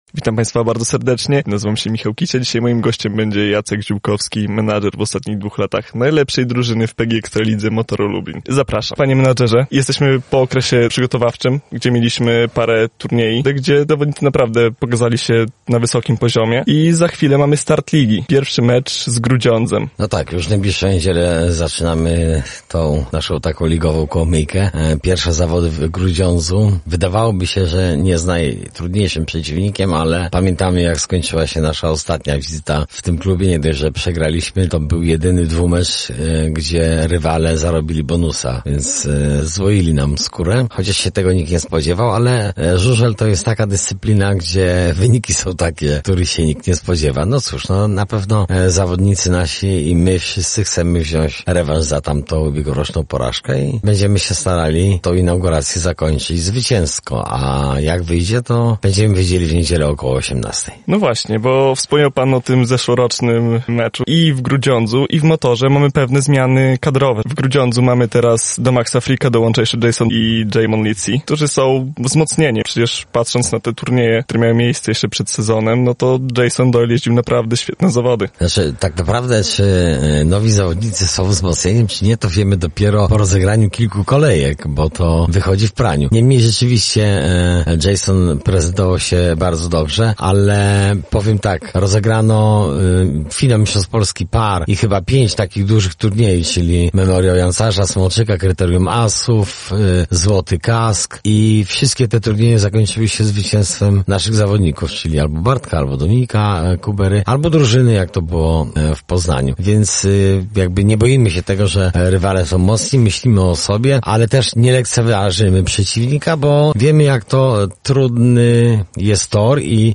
Koziołki w drodze po kolejne złoto? Przedsezonowa rozmowa